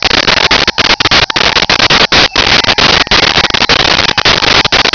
Sfx Amb Jungle A Loop
sfx_amb_jungle_a_loop.wav